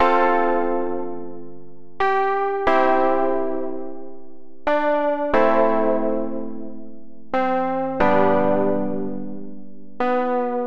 洛菲罗兹
描述：寒冷的钢琴罗德斯一类的东西？
Tag: 90 bpm Chill Out Loops Piano Loops 1.79 MB wav Key : A